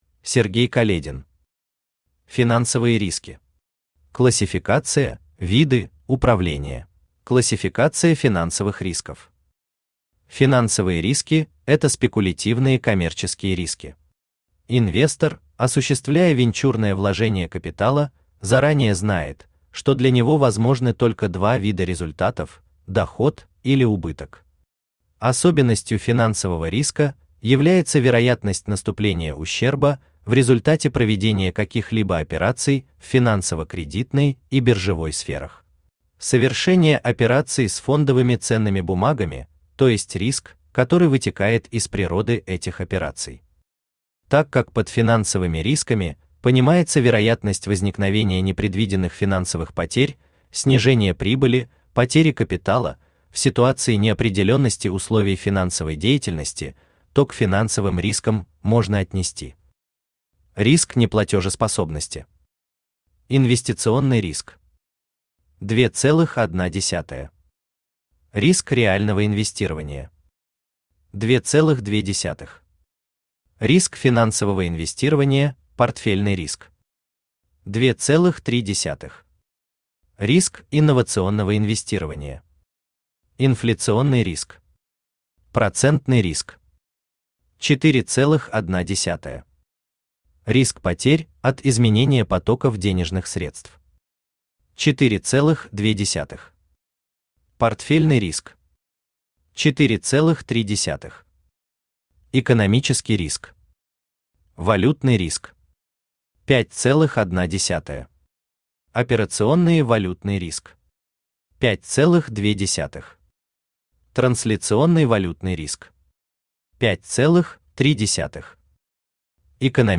Аудиокнига Финансовые риски. Классификация, виды, управление | Библиотека аудиокниг
Классификация, виды, управление Автор Сергей Каледин Читает аудиокнигу Авточтец ЛитРес.